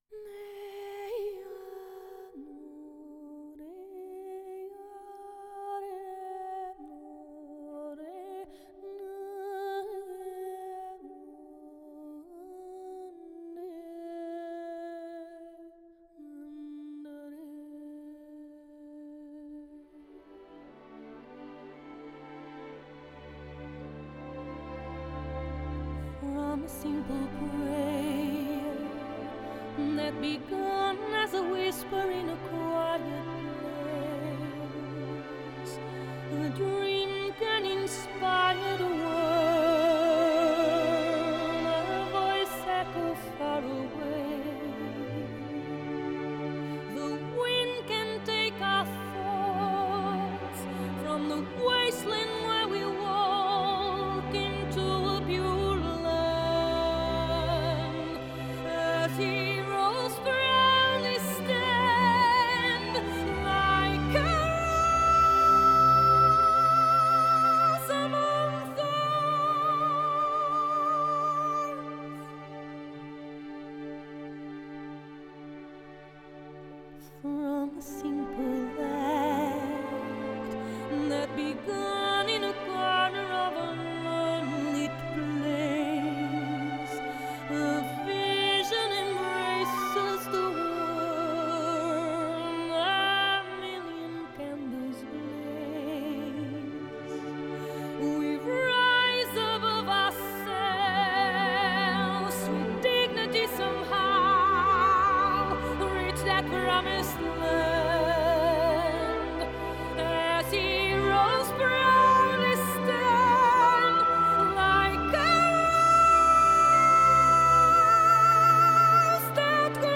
Genre: Score
編寫全新的管弦樂曲